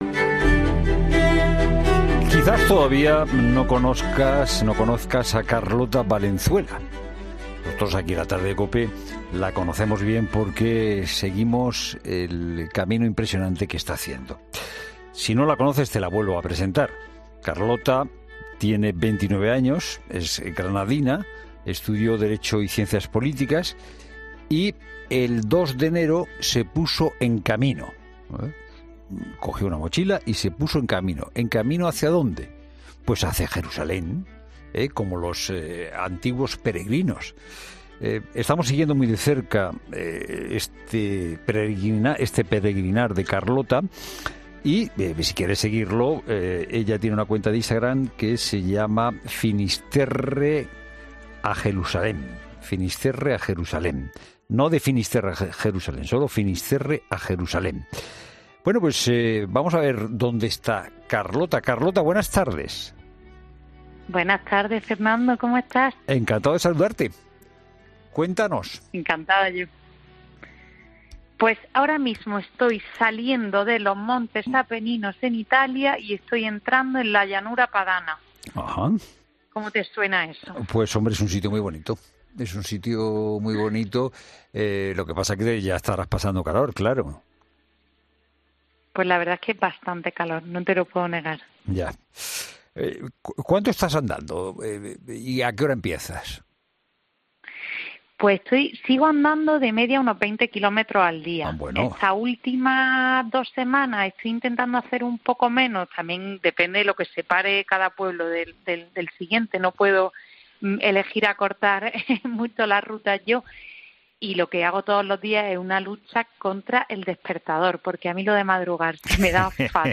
De hecho, estamos siguiendo su camino y ha sido entrevistada en 'La Tard e'.